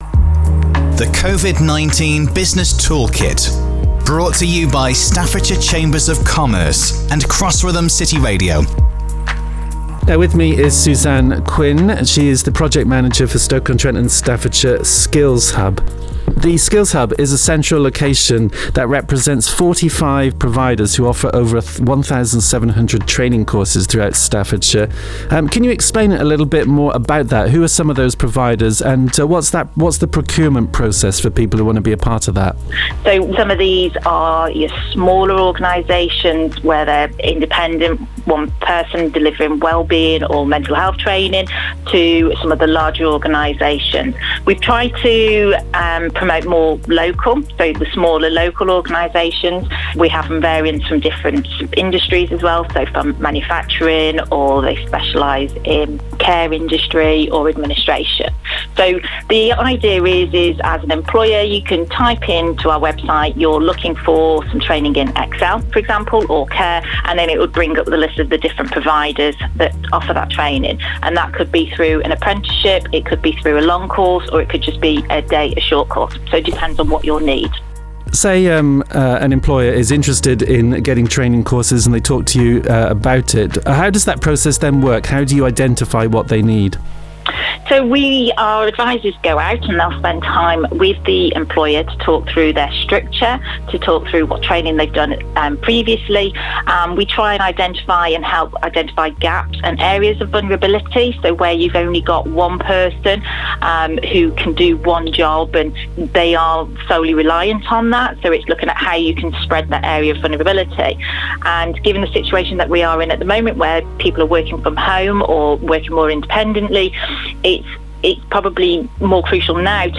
Each podcast features interviews with specialists from Staffordshire Chambers of Commerce and covers topics that businesses may find informative, such as: Funding advicejob retention & self employed schemesonline mentoringbusiness crime support and more...